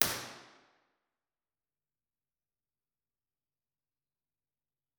IR_HolyTinity_Mono.wav